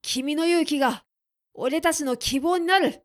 ボイス
パワフル男性
dansei_kiminoyuukigaoretatinokibouninaru.mp3